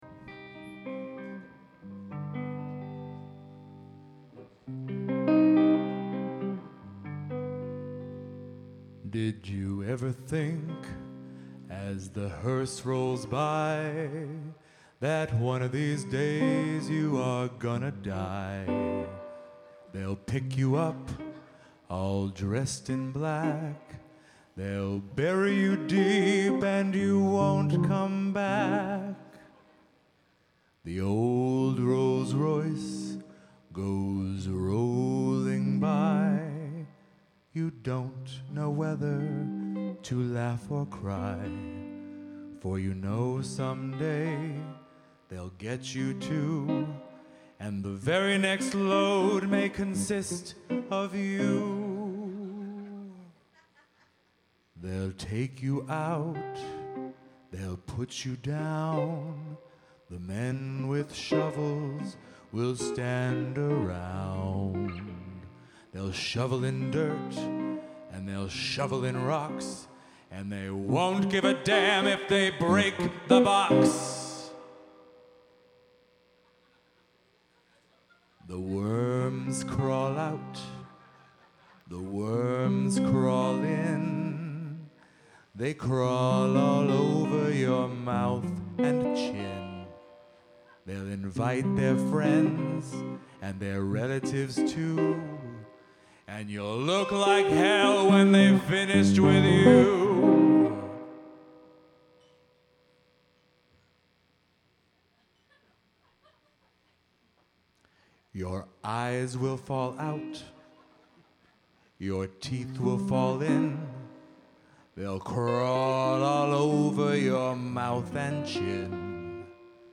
April 8, 2007 at The Box